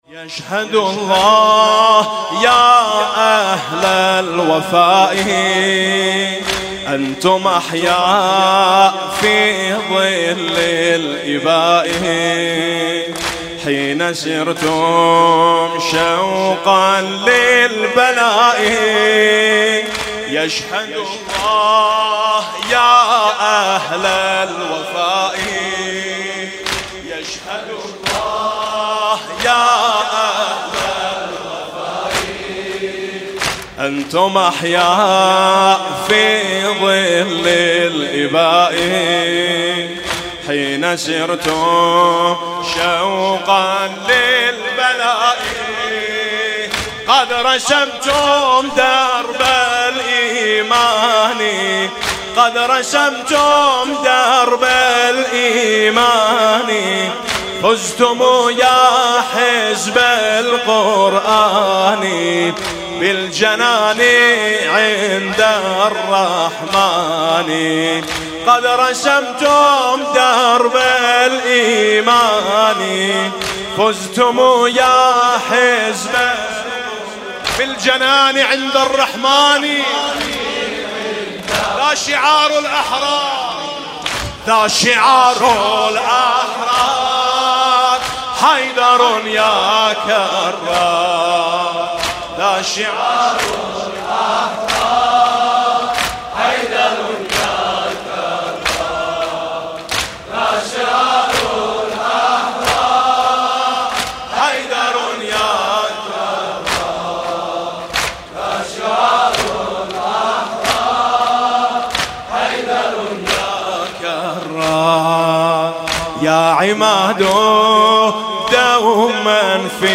دانلود مداحی عربی به یاد شهدای مقاومت لبنان (ذا شِعارُ الأَحرار: حَيدرٌ يا کَرّار) | برادر میثم مطیعی